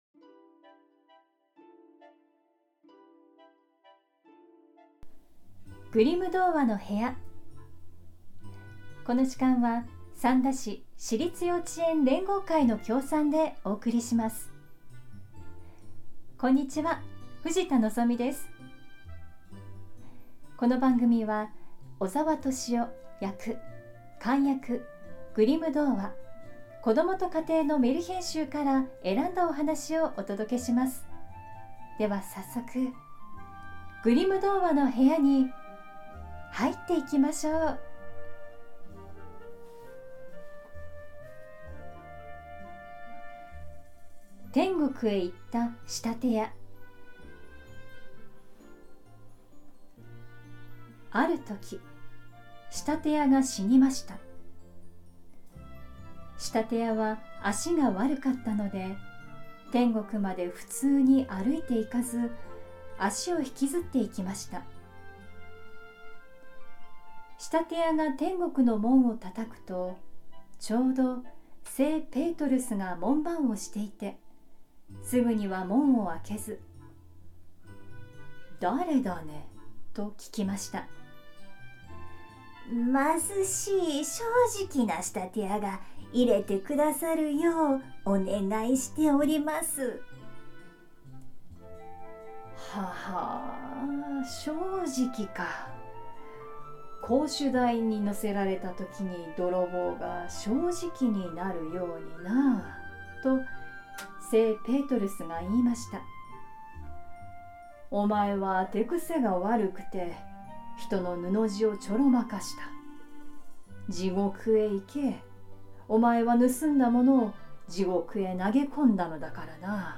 グリム兄弟によって集められたメルヒェン（昔話）を、翻訳そのままに読み聞かせします📖